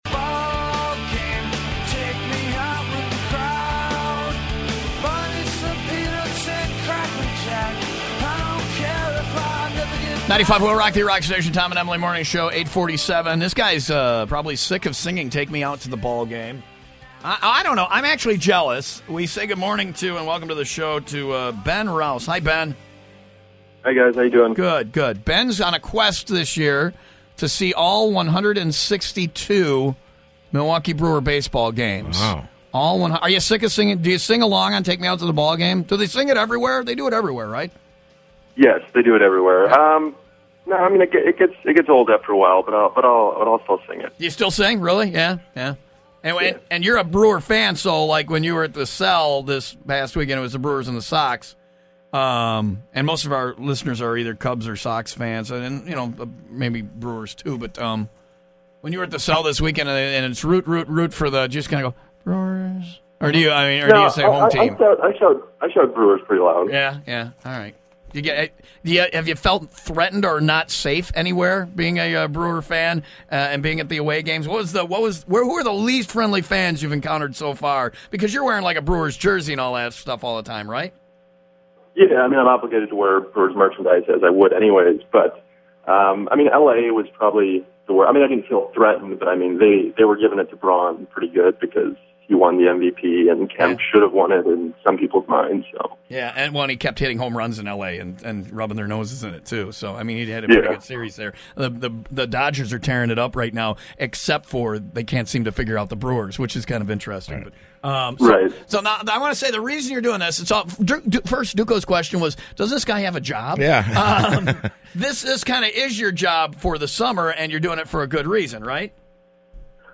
I'll start with the radio interview I did on Monday morning.
interview-on-95-wiil-rock-1.mp3